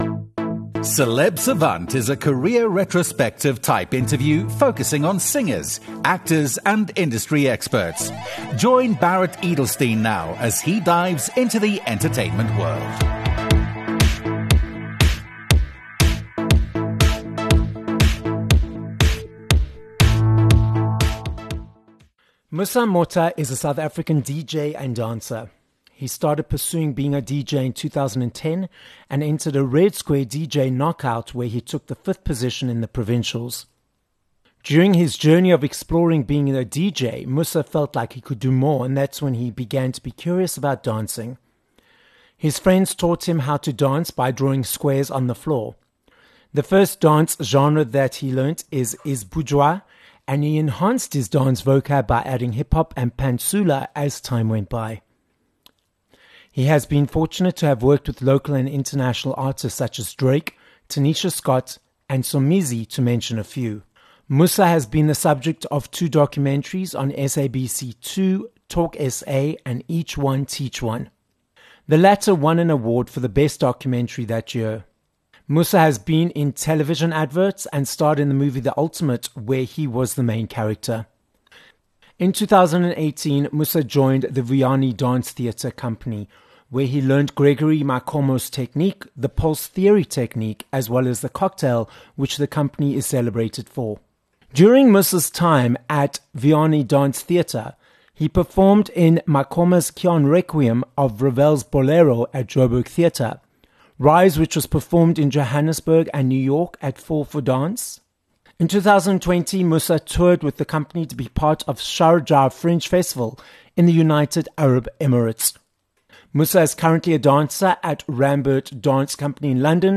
7 Apr Interview with Musa Motha